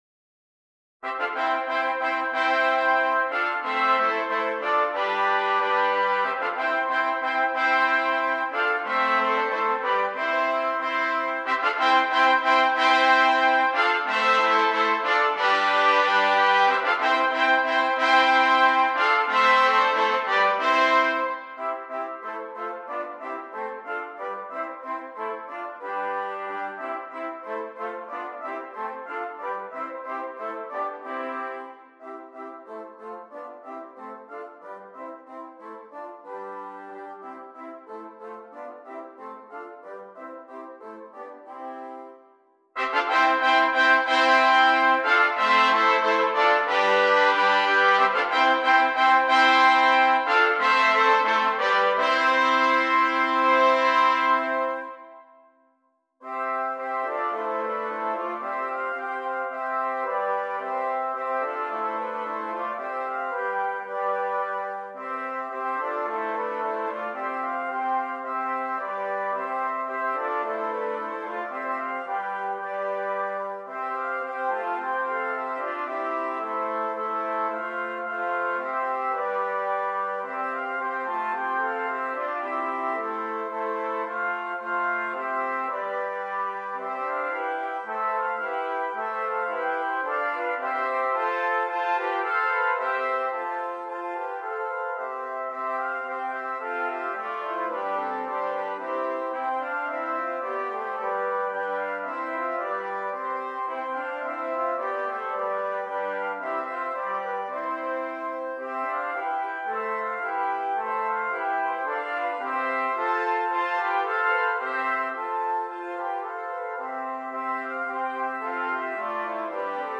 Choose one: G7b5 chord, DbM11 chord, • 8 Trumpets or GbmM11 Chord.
• 8 Trumpets